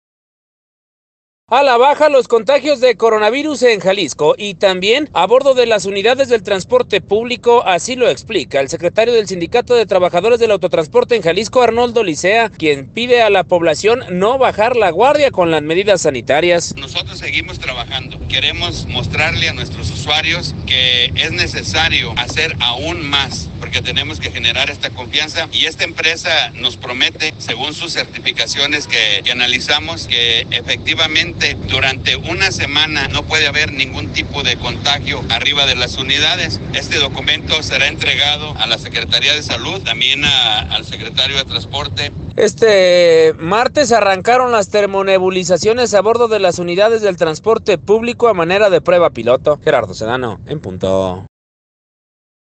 Sí participaba en carreras clandestinas la camioneta que generó un accidente mortal registrado durante el fin de semana en López Mateos, donde perdieron la vida tres jóvenes, entre ellos, una mujer. Al respecto habla el secretario de Seguridad Pública, Juan Bosco Pacheco: